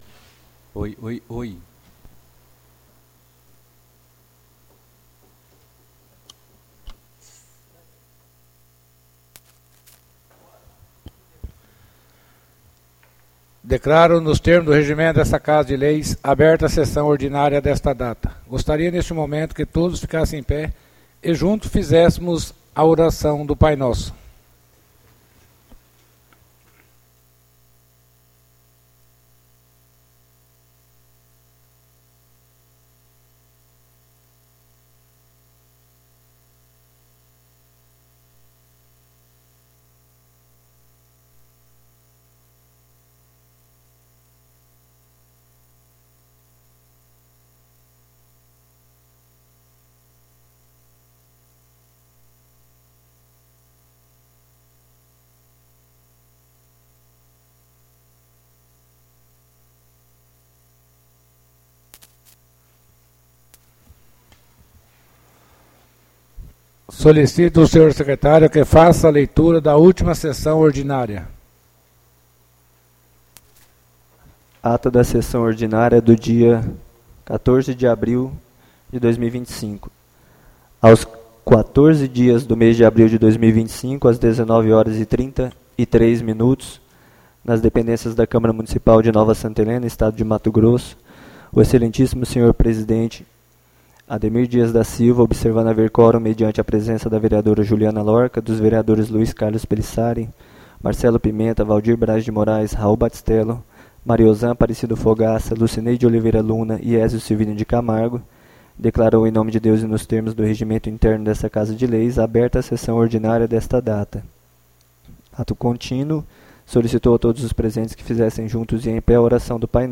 ÁUDIO SESSÃO 28-04-25